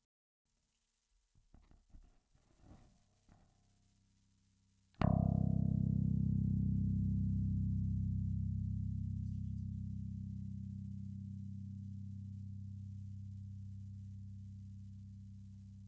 Наводки/фон по сети при записи